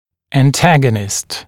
[æn’tægənɪst][эн’тэгэнист]антагонист, противоположный зуб